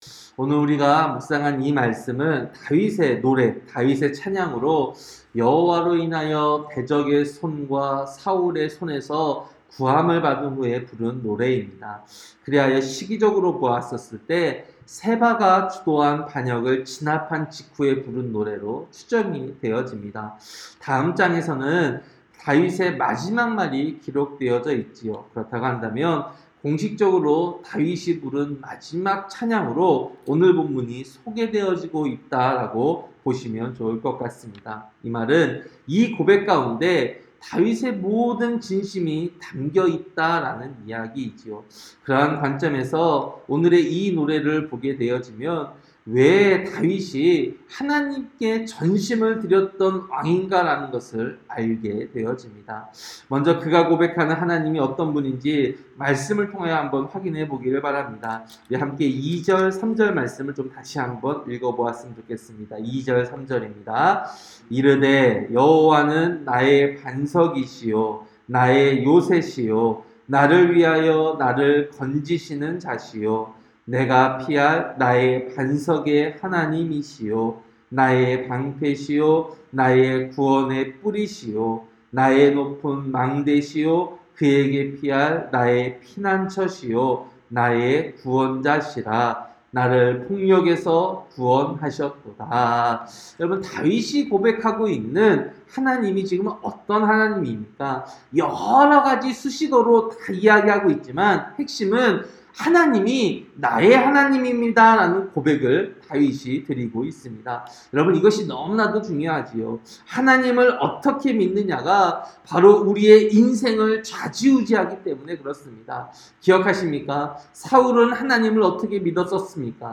새벽설교-사무엘하 22장